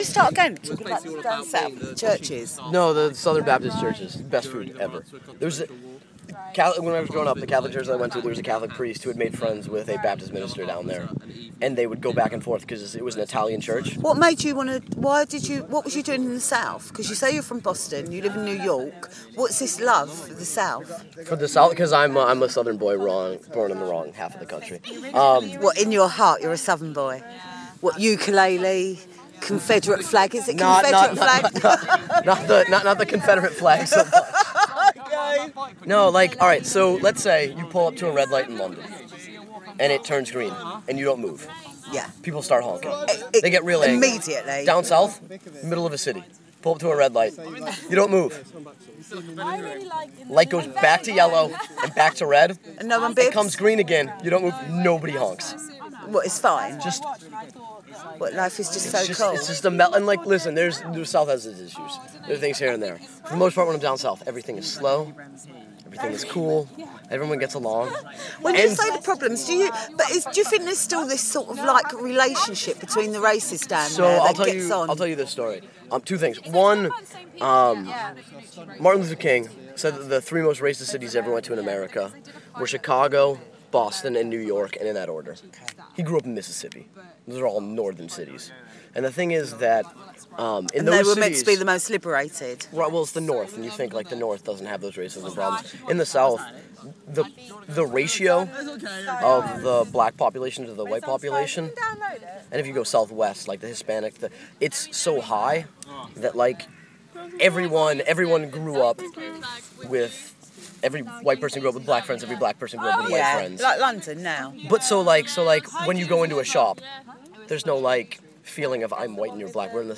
My American boys from the MA course discuss Race relations and the South